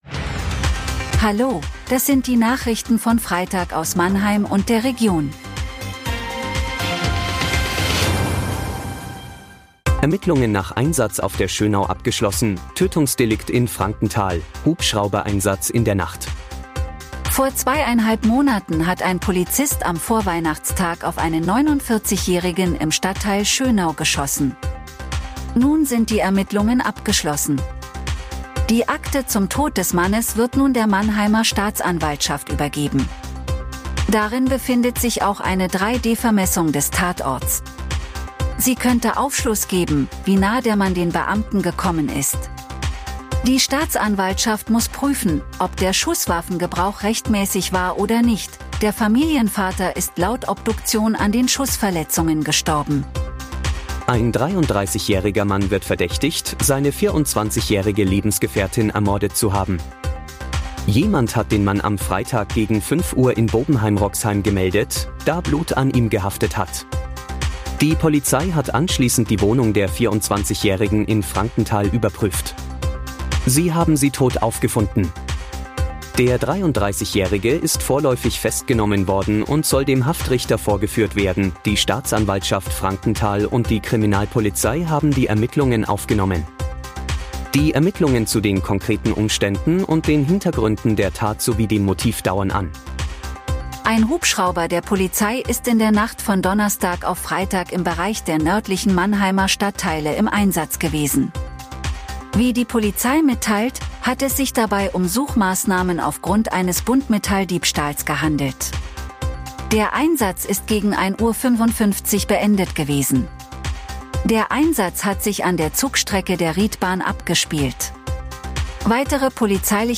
Der Nachrichten-Podcast des MANNHEIMER MORGEN
Nachrichten